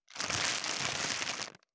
629コンビニ袋,ゴミ袋,スーパーの袋,袋,買い出しの音,ゴミ出しの音,袋を運ぶ音,